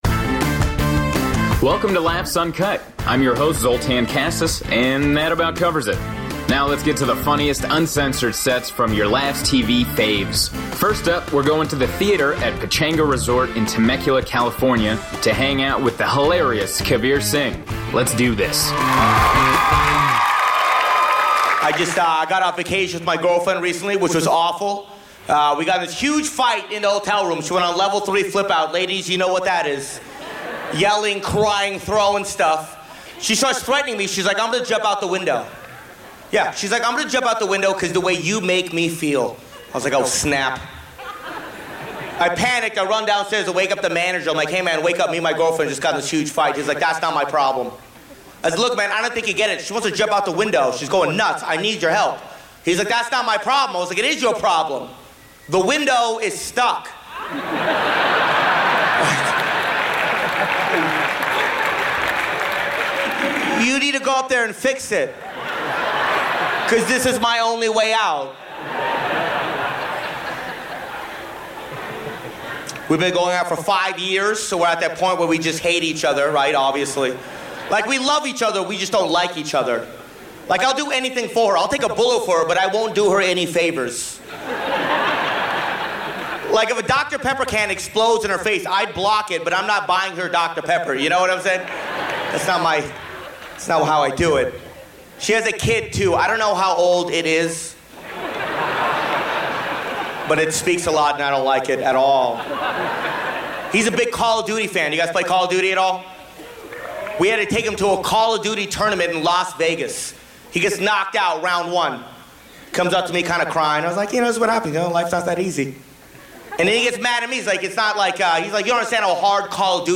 Comedians